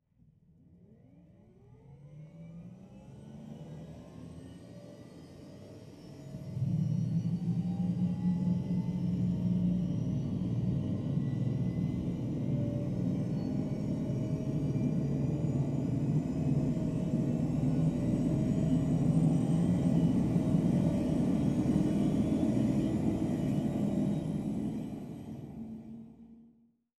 H145_Starter-left.wav